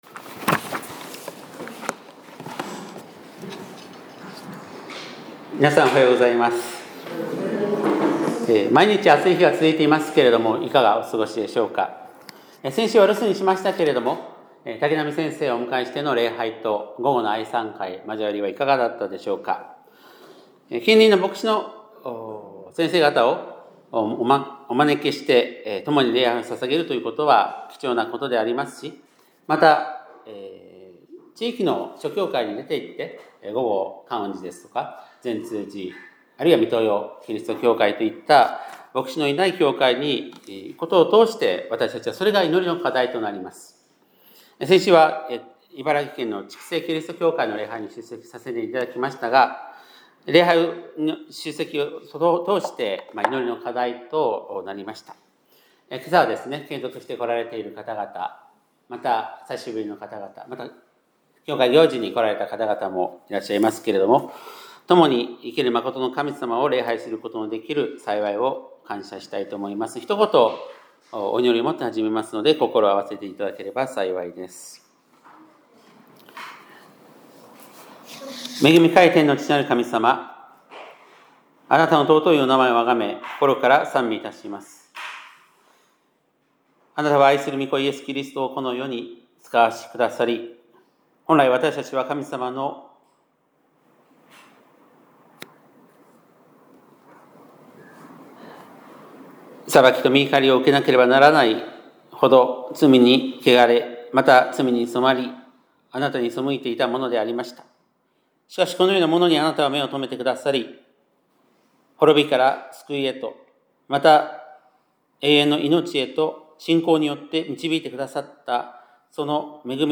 2025年7月6日（日）礼拝メッセージ